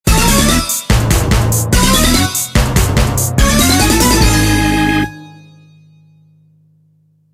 Wario team jingle